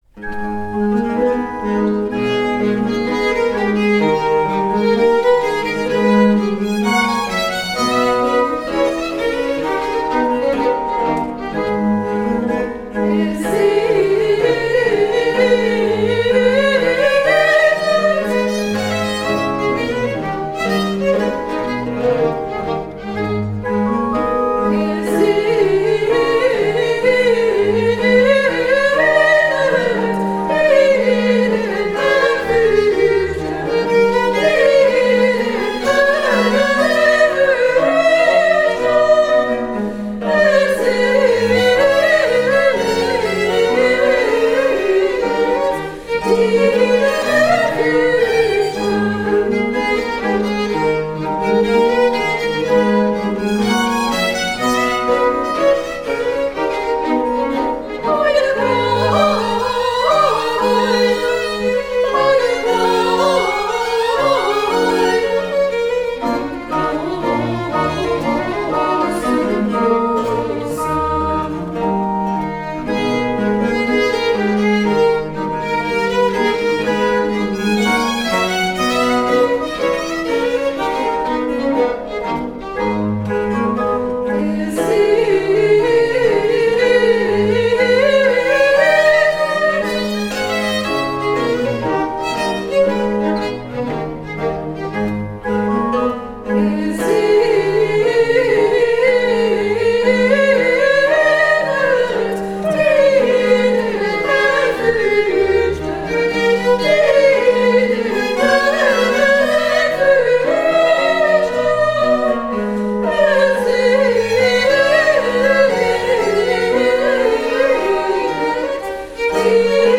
Aria n°3 - Soprano
9-Bach_BWV_196_aria_n_3_Soprane.mp3